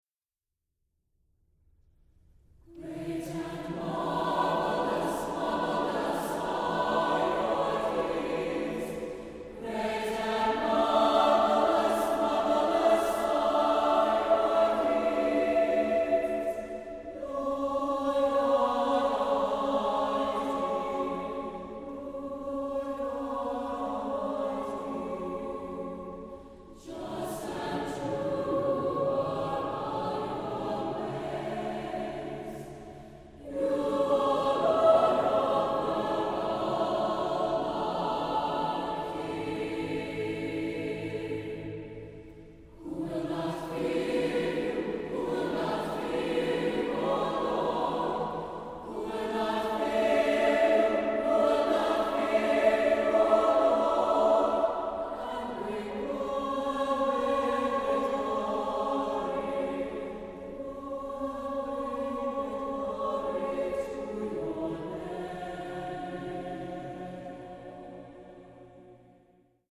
Here’s an excerpt from a previous NCYC.
Choristers say it “has a deeper meaning” and they like the “harmonic complexity and dissonance.” It is “very dramatic and easy to get into” and the “mood is amazing.”